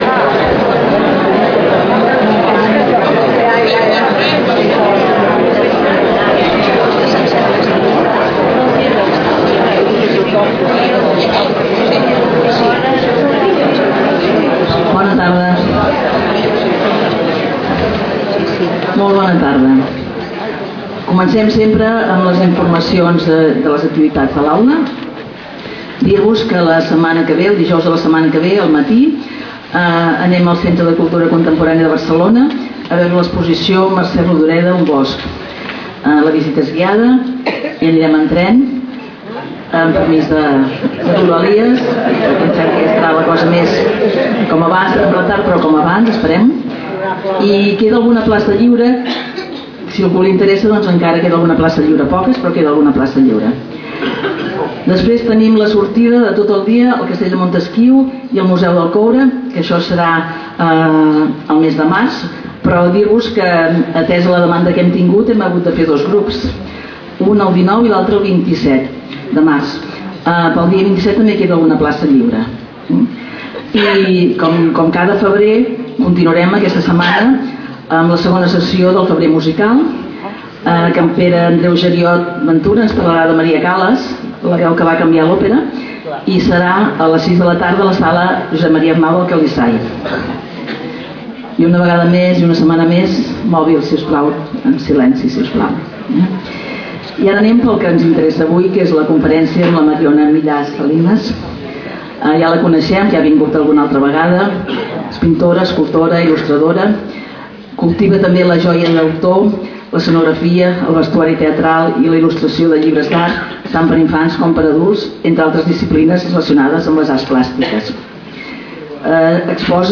Lloc: Casal de Joventut Seràfica
Conferències